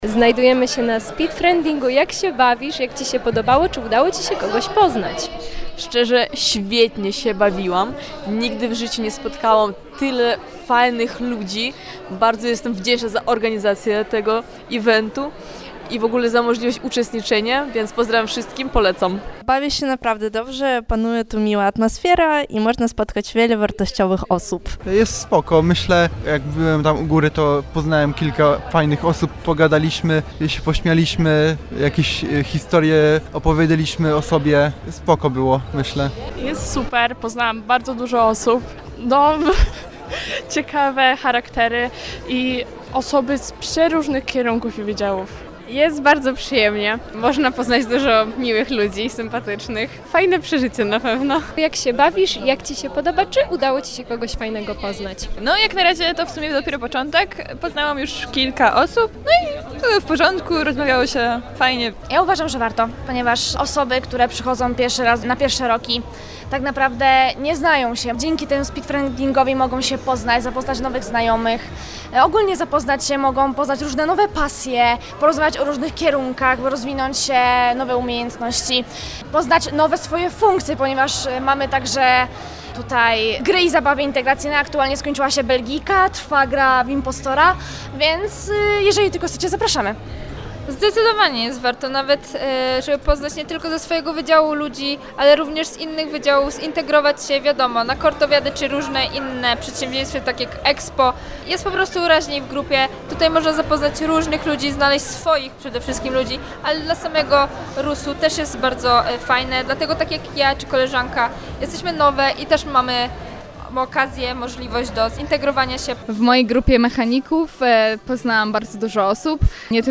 Nasza reporterka rozmawiała także z uczestnikami Speed Friendingu.